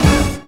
SWINGSTAB 5.wav